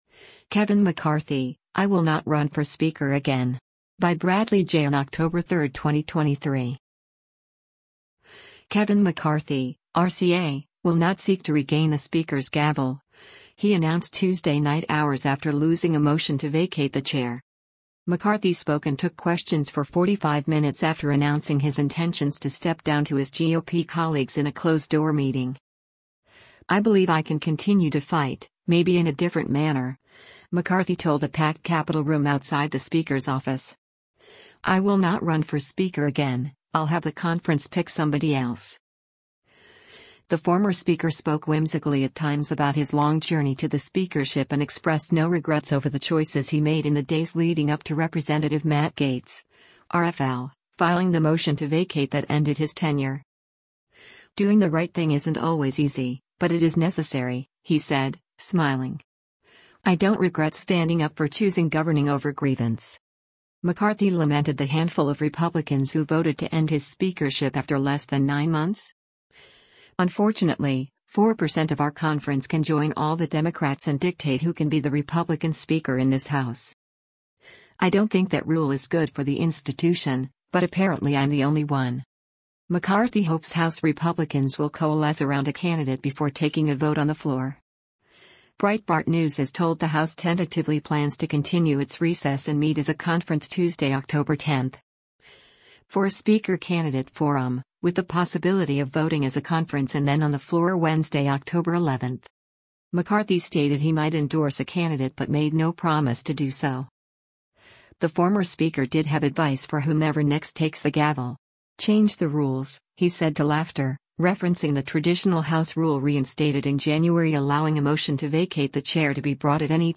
McCarthy spoke and took questions for 45 minutes after announcing his intentions to step down to his GOP colleagues in a closed-door meeting.